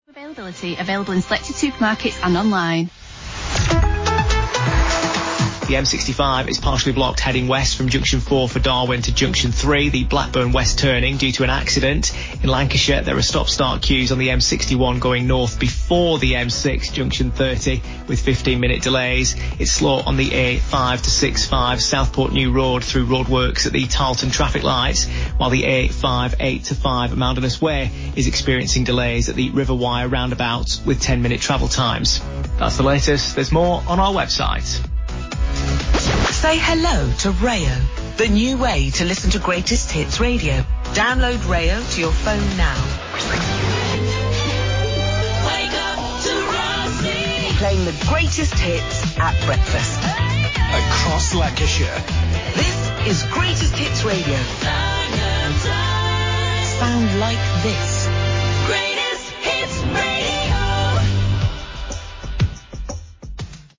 Most of the traffic bulletins on Greatest Hits Radio and Hits Radio are now voiced by computers rather than people.
Bulletins are generated through INRIX’s traffic intelligence and automation systems, with delivery informed by the tone and style of our experienced broadcast journalists to ensure continuity and familiarity for listeners.
Here are some examples of the bulletins.
Lancashire-Travel-Example.mp3